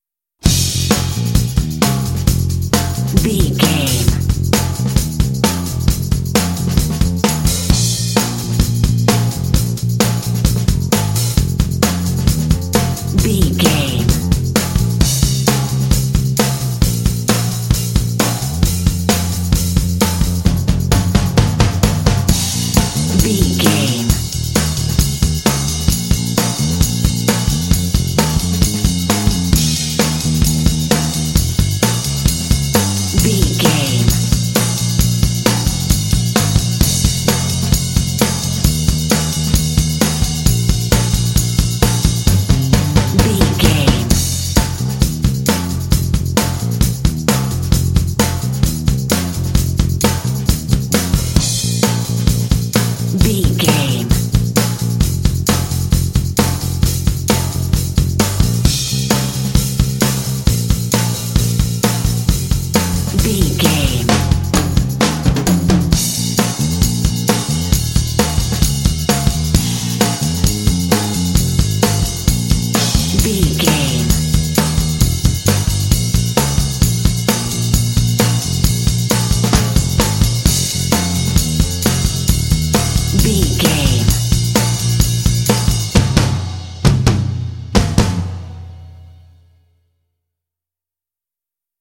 Uplifting
Ionian/Major
bouncy
happy
electric guitar
drums
bass guitar
saxophone